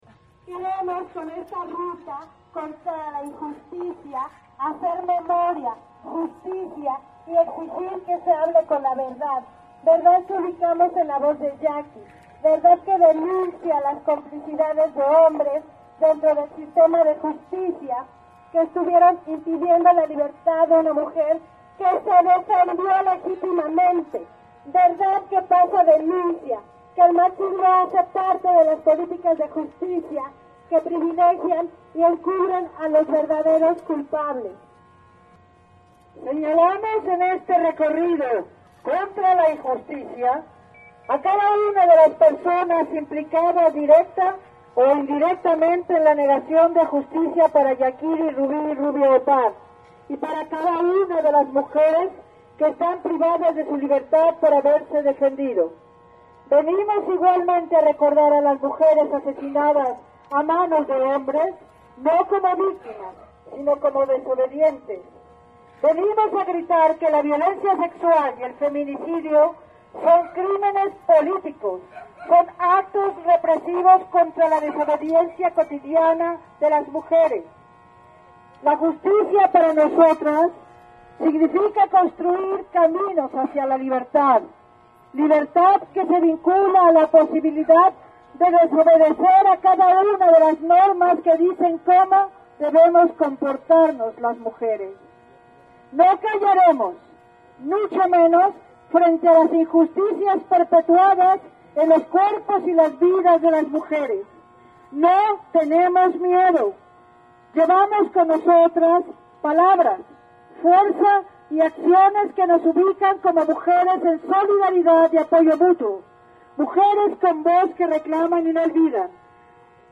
Marcha contra la injusticia.